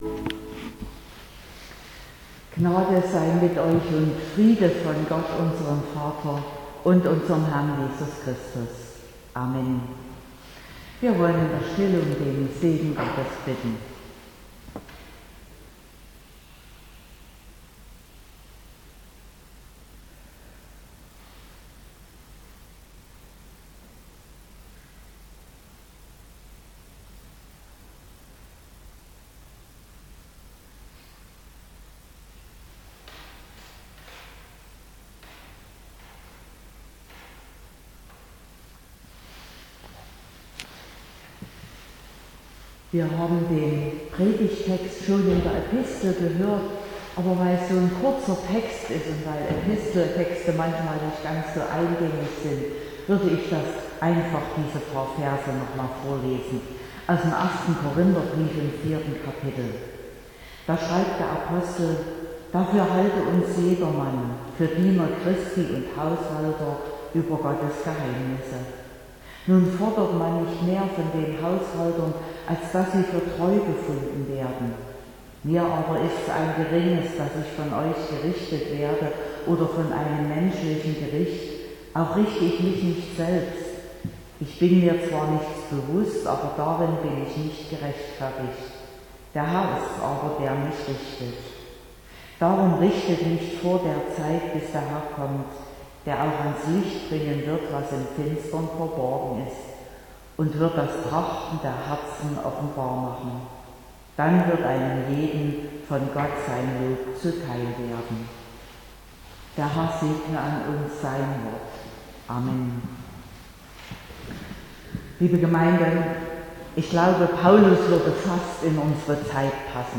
Predigt und Aufzeichnungen